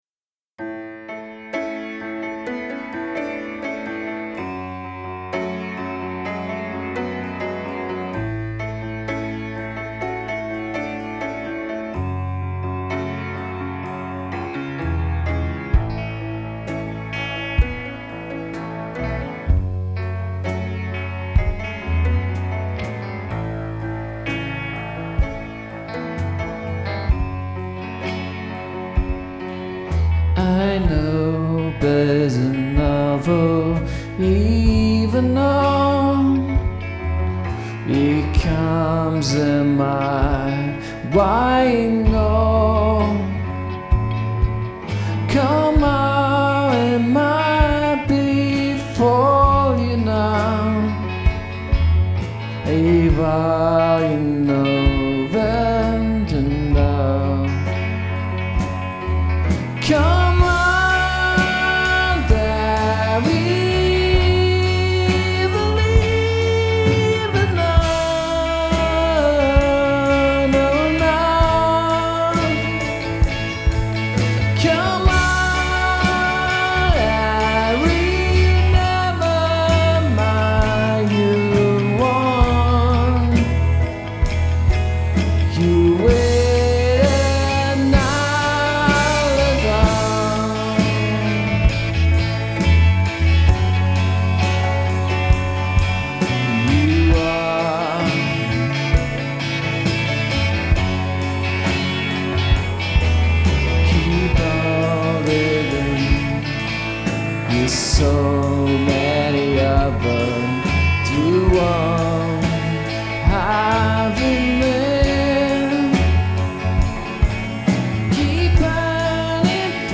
It sounds a bit more "live" than most of the other things I've done due to the way we recorded it... the vocals, piano and drums
and then we both simultaneously recorded the bass and guitar
A bit of mixing later and it was done.
nice texture, epic chorus. this should go on the lyrics queue